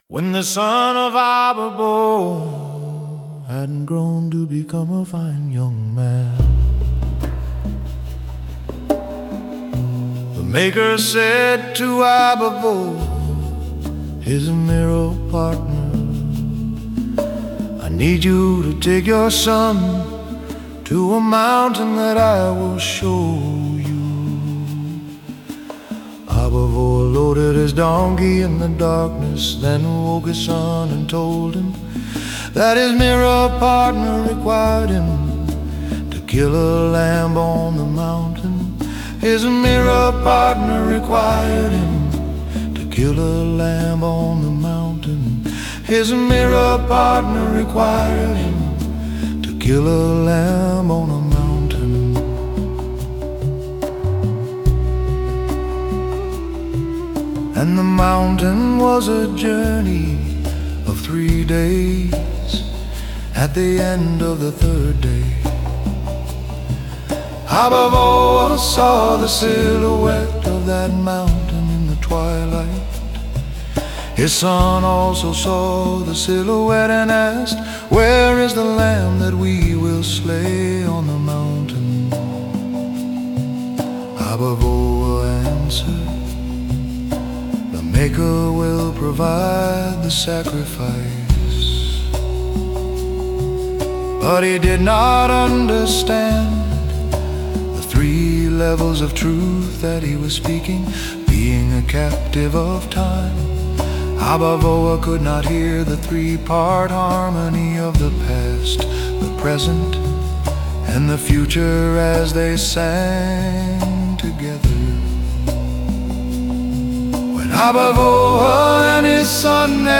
“Way Back in the Long Ago” is a campfire story told by a group of old men.